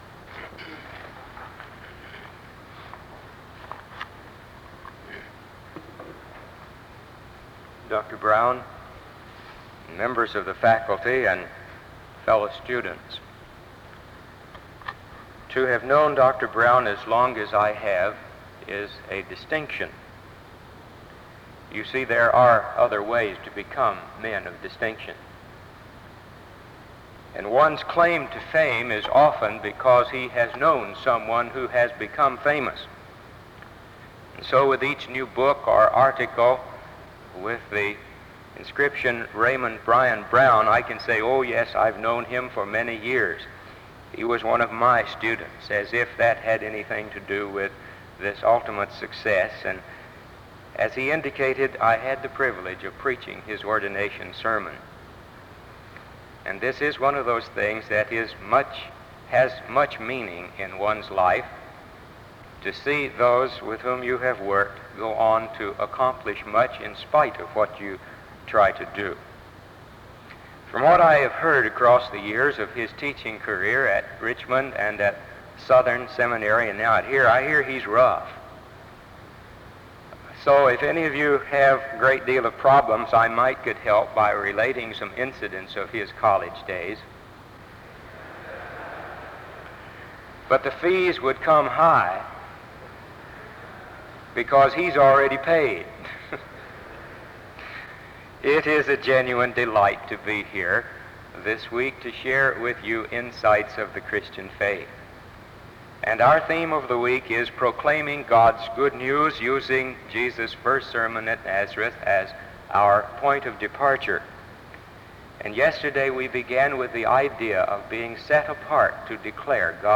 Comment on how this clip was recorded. A brief rewind occurs, and a low shrill follows the audio to its end (26:00-31:19).